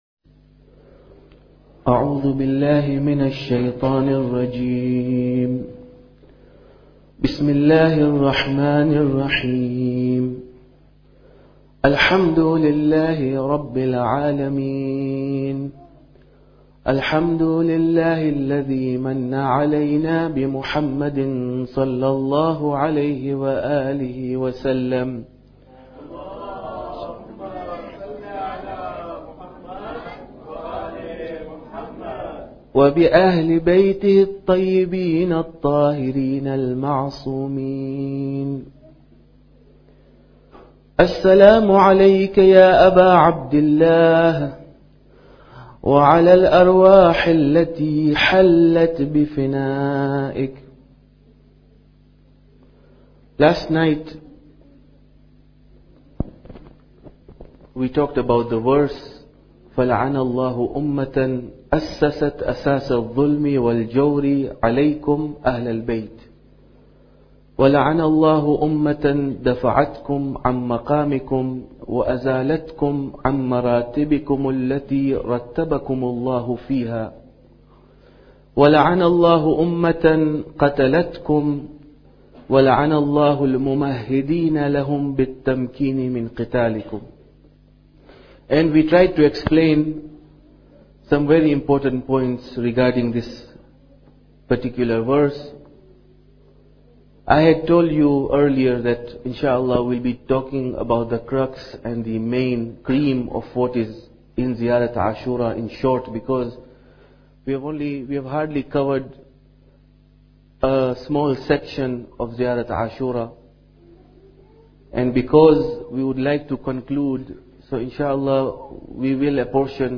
Muharram Lecture Ashura Day 10